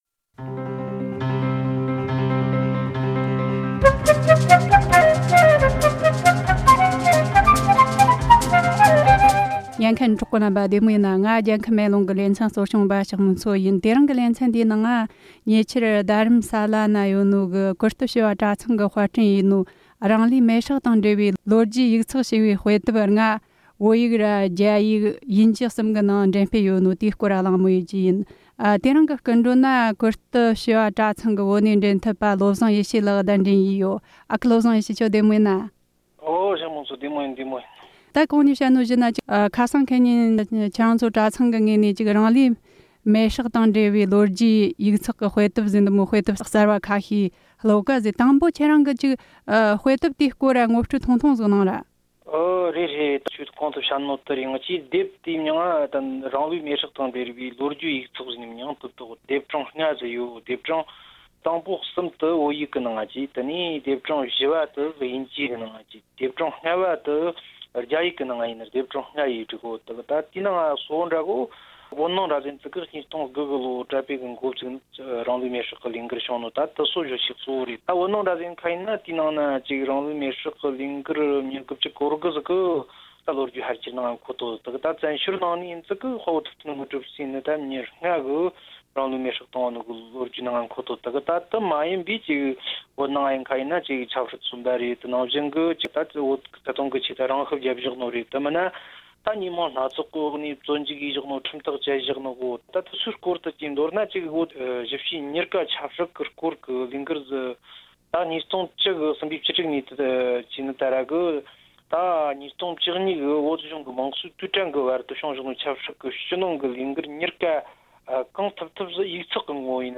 སྒྲ་ལྡན་གསར་འགྱུར།
བཅར་འདྲི